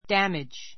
damage dǽmidʒ ダ メ ヂ 名詞 損害, 被害 ひがい , 傷害, ダメージ cause [do] damage to ～ cause [ do ] damage to ～ ～に損害[被害]を与 あた える ⦣ × a damage, × damage s としない. suffer [receive] damage suffer [ receive ] damage 被害を受ける 動詞 損害[被害]を与える, 傷つける